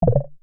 notification.wav